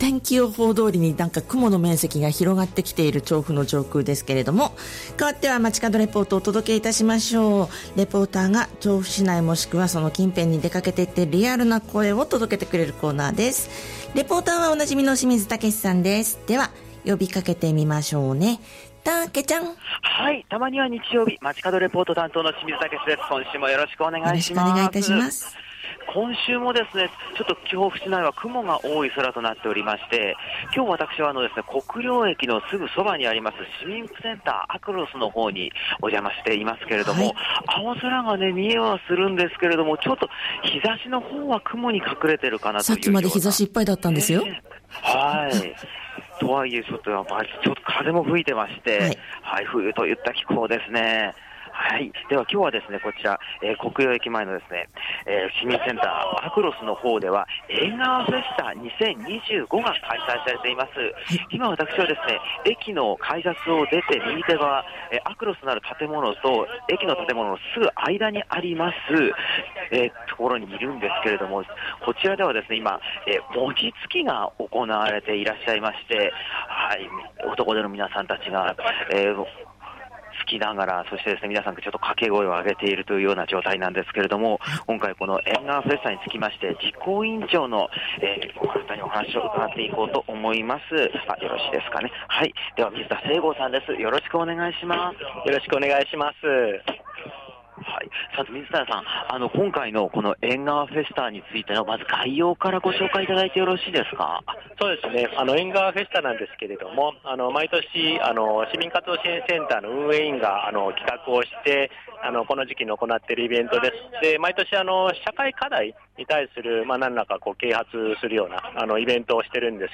曇り空、真冬の気候の空の下からお届けした街角レポートは、 調布市民プラザあくろすで開催中の「えんがわフェスタ2025」からお届けしました！